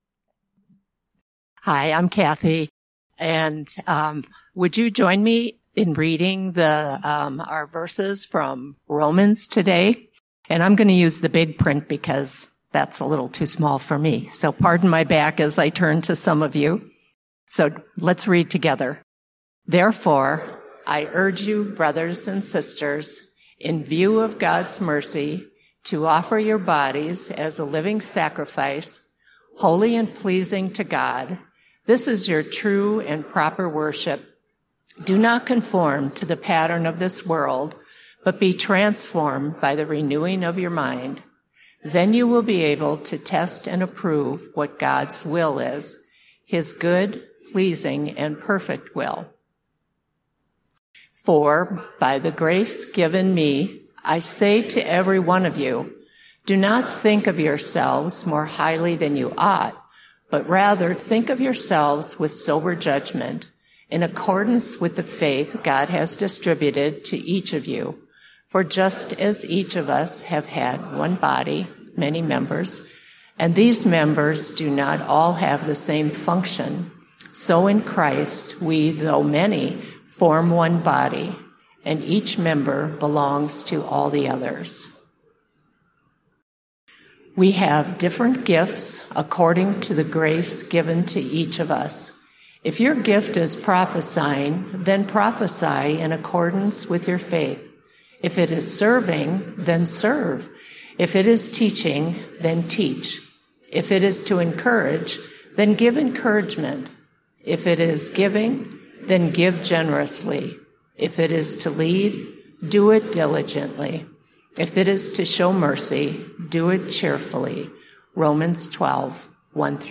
This podcast episode is a Sunday message from Evangel Community Church, Houghton, Michigan, November 17, 2024.